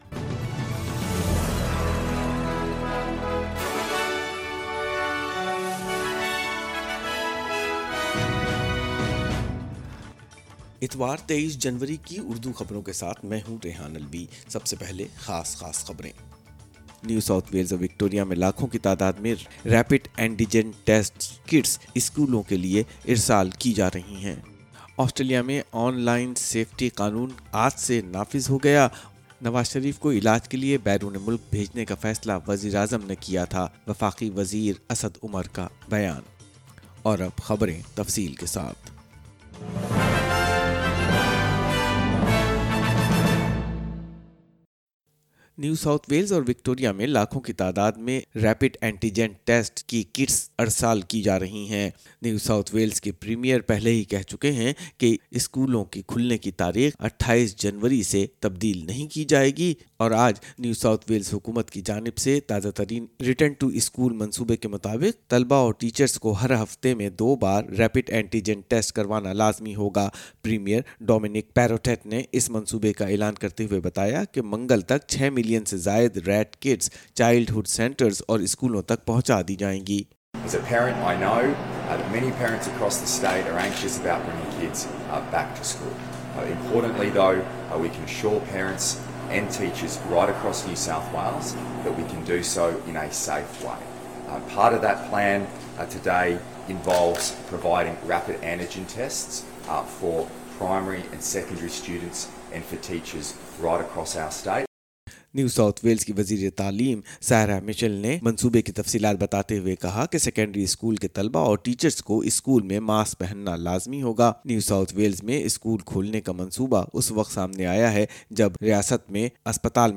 Urdu News 23 January 2022-NSW announced return to school plan